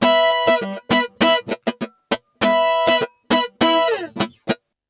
100FUNKY07-R.wav